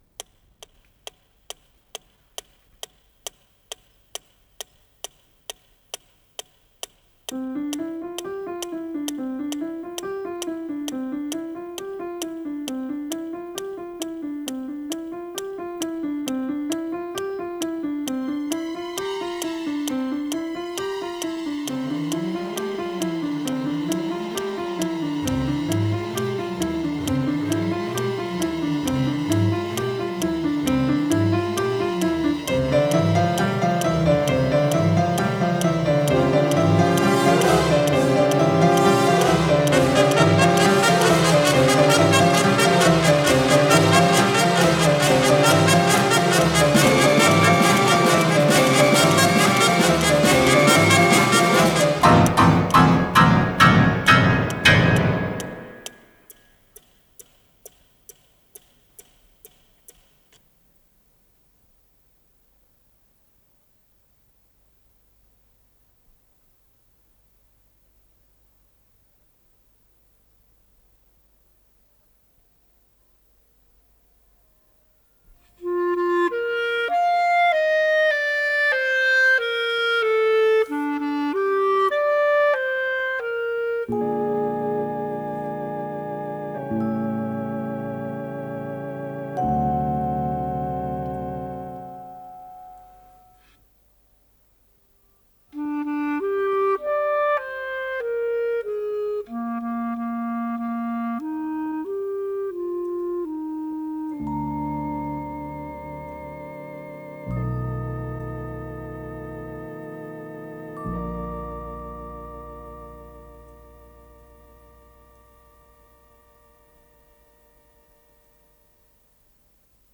музыка к спектаклю